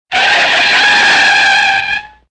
На этой странице собраны реалистичные звуки тормозов автомобилей: от резкого визга до плавного скрипа.
Тормоз машины - Альтернативный вариант